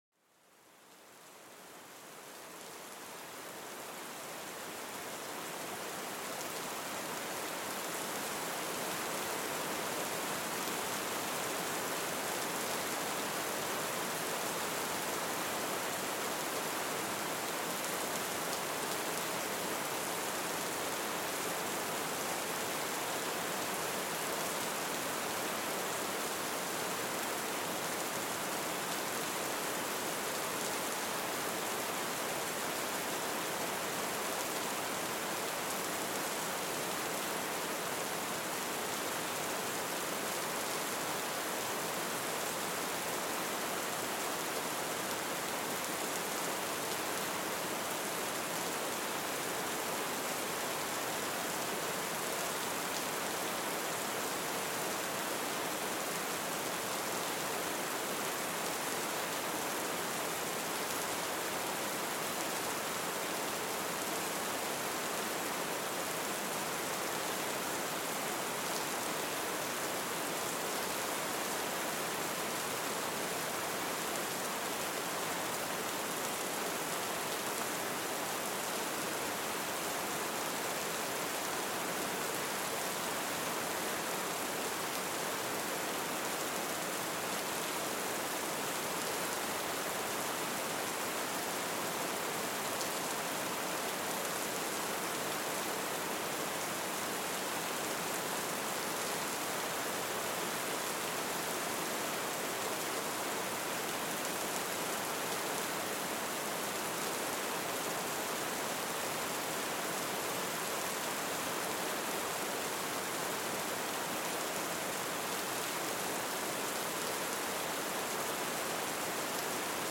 Le doux murmure de la pluie : apaisez l'esprit et relâchez les tensions